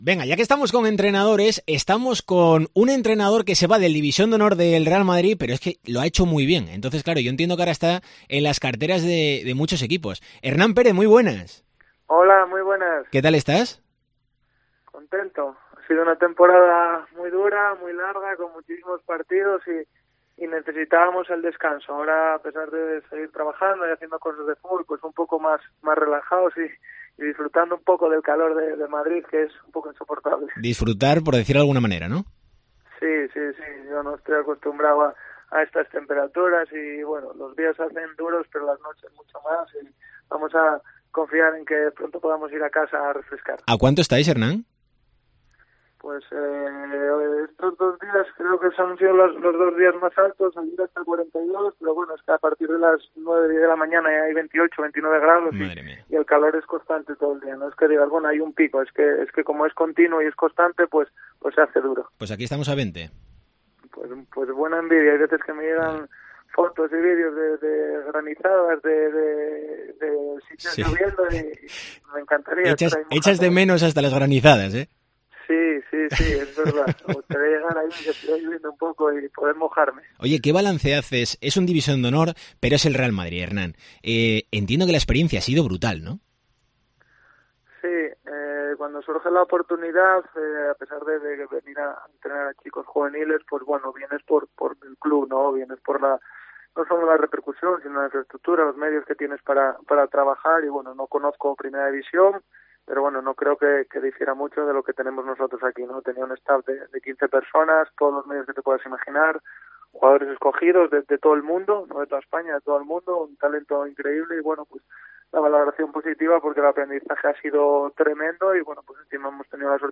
ENTREVISTA DCA